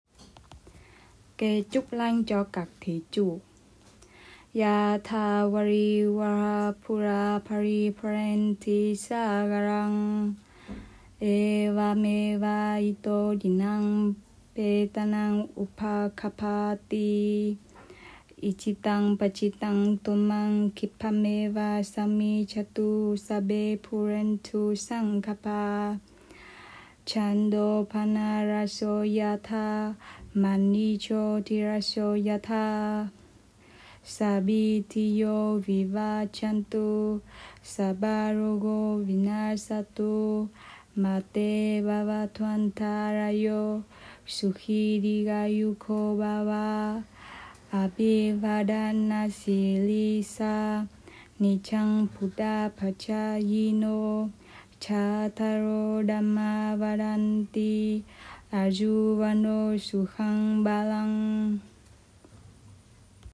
Kệ-chúc-lành-cho-các-thí-chủ-pali.m4a